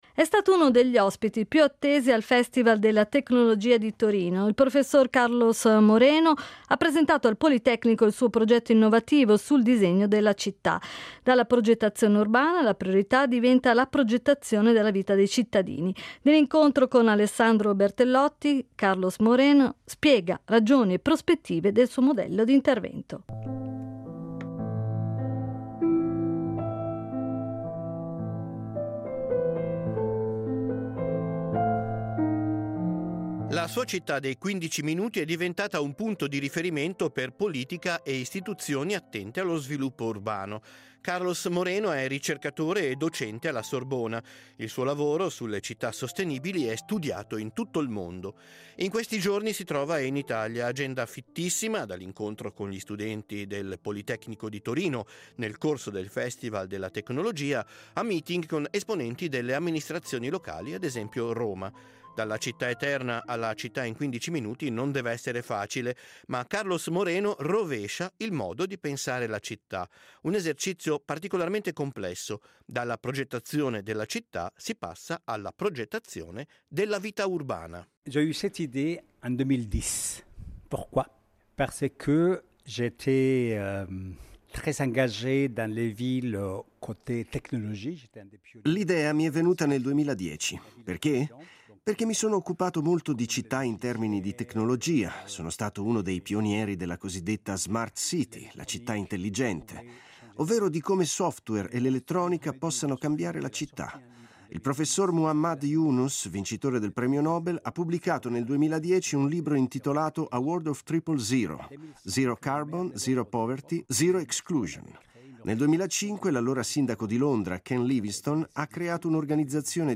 Dal palco del Festival della Tecnologia di Torino, Carlos Moreno, Professore Associato presso la Paris IAE – Panthéon Sorbonne, ha presentato al Politecnico il suo progetto innovativo sul disegno della città. Dalla progettazione urbana, la priorità diventa la progettazione della vita dei cittadini.